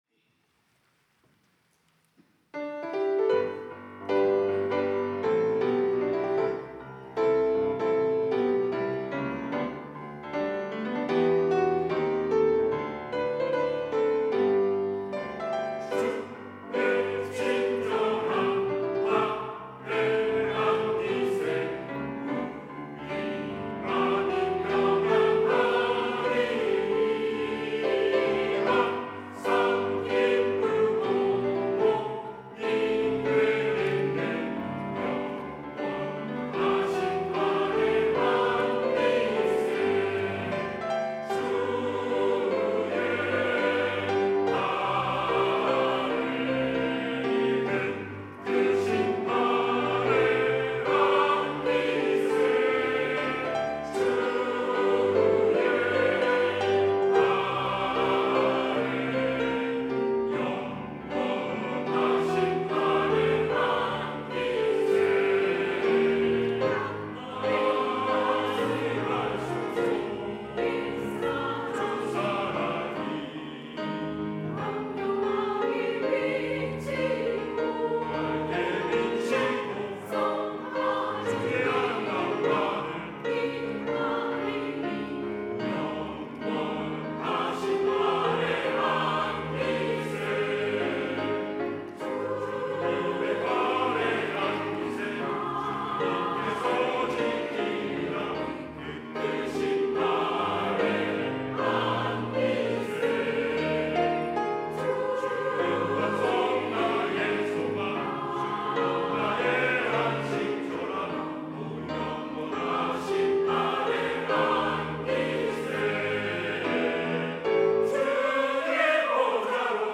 시온(주일1부) - 주의 친절한 팔에 안기세
찬양대 시온